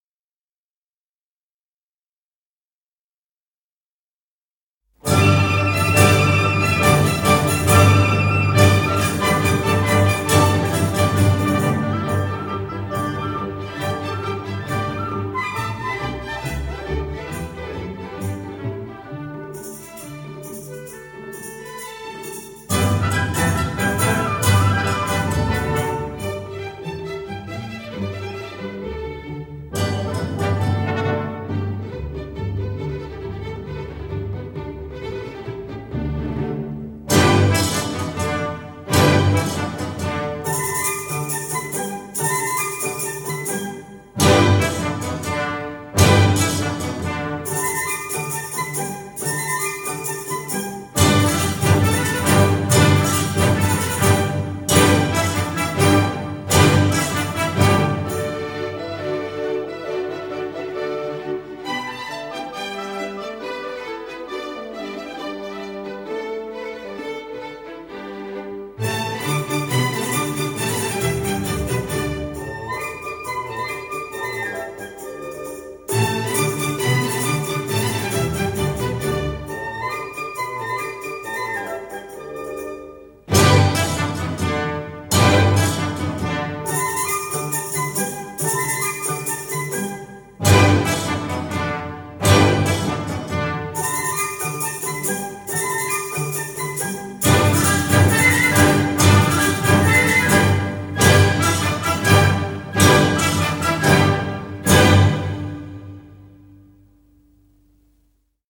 第一段强烈的主体出现在高音区。